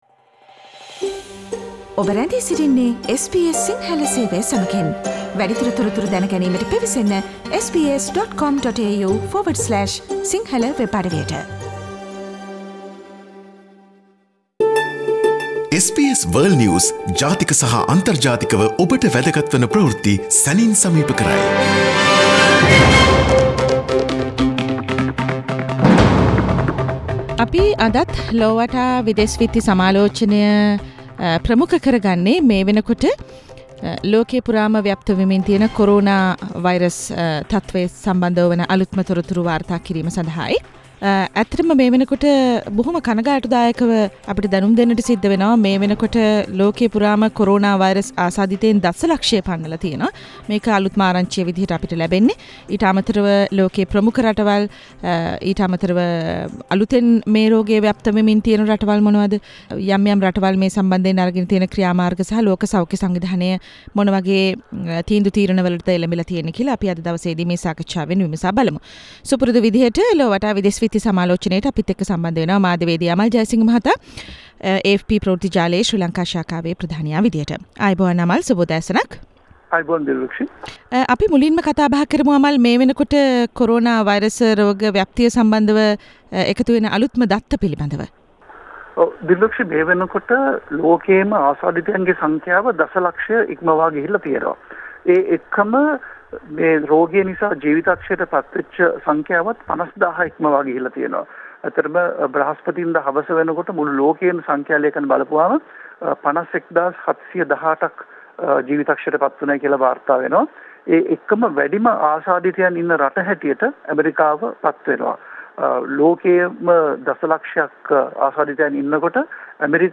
weekly world news wrap - Source: SBS Sinhala radio